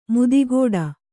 ♪ mudigōḍa